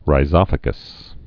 (rī-zŏfə-gəs)